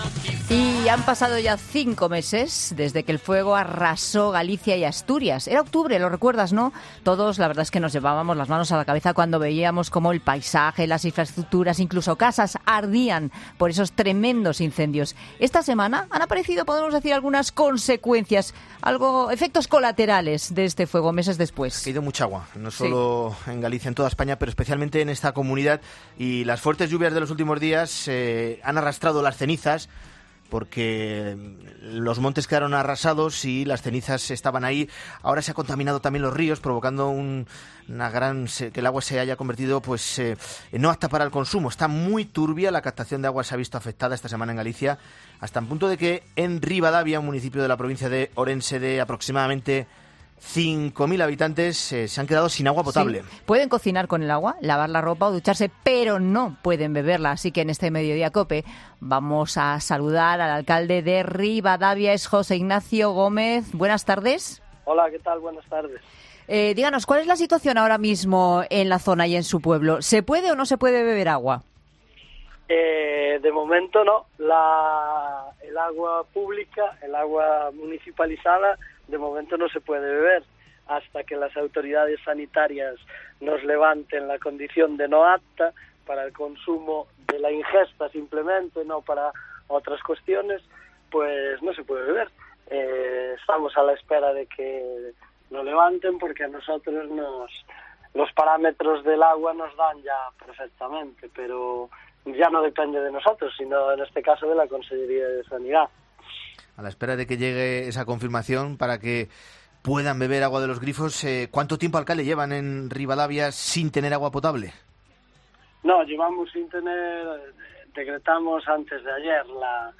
José Ignacio Gómez Pérez, alcalde de Ribadavia